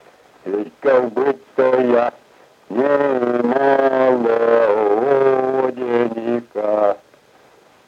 Произношение слова будто как быдто
/в’е”д’ ка-кбы”-дто йа” н’ее-мо-лооо”-д’е-н’ка:/